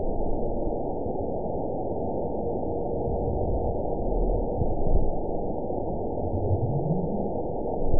event 920127 date 02/24/24 time 02:33:58 GMT (1 year, 2 months ago) score 9.62 location TSS-AB03 detected by nrw target species NRW annotations +NRW Spectrogram: Frequency (kHz) vs. Time (s) audio not available .wav